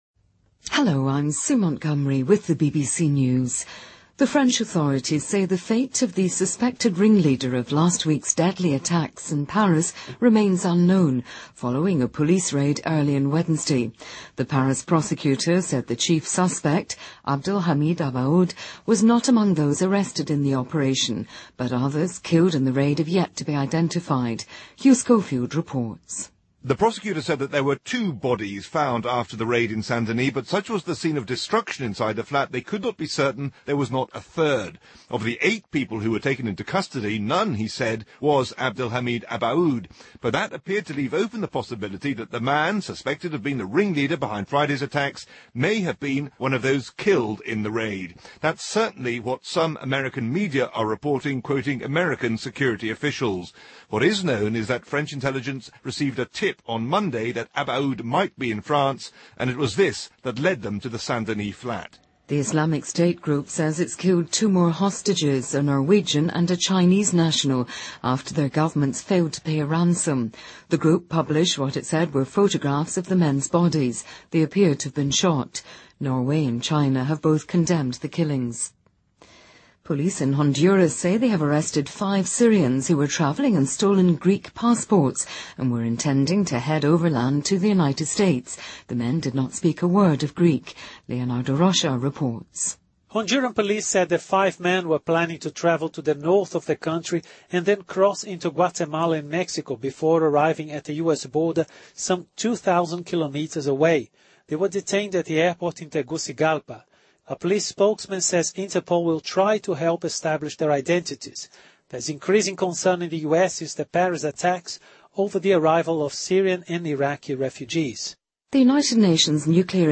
BBC news,伊斯兰国宣称处决中国和挪威人质
日期:2015-11-20来源:BBC新闻听力 编辑:给力英语BBC频道